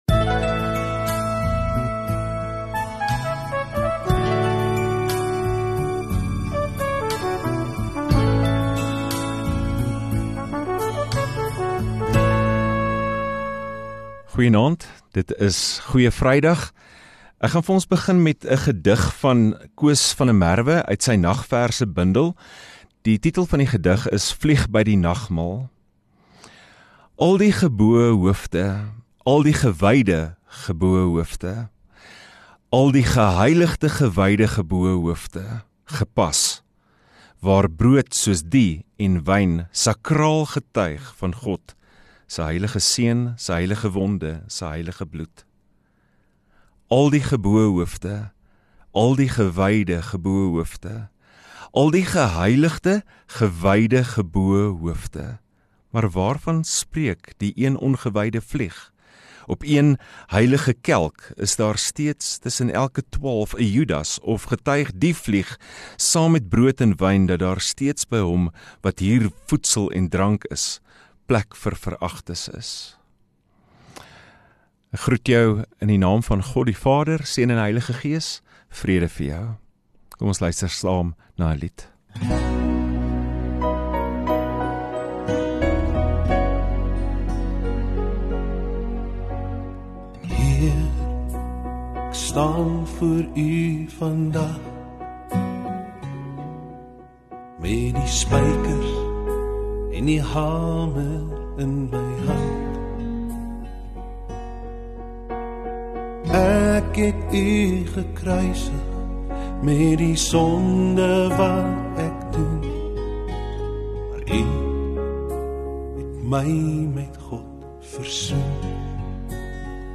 29 Mar Vrydagaand Erediens